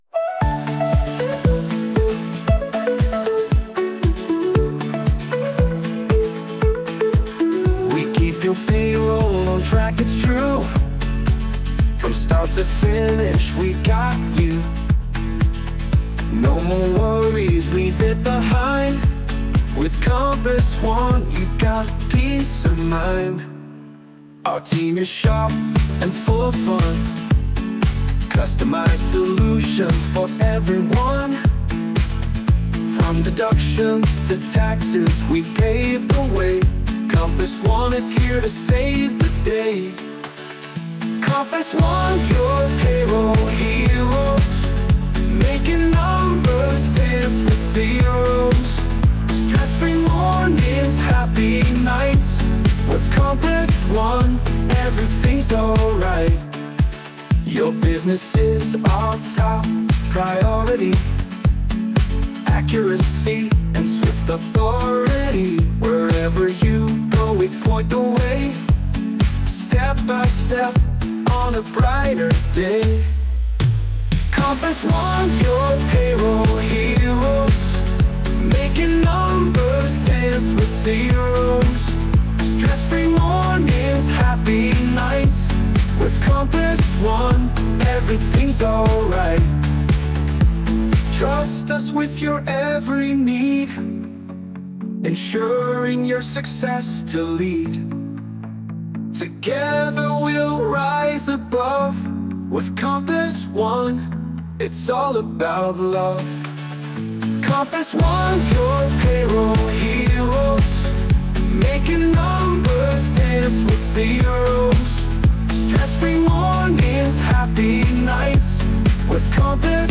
Help us choose our new jingle.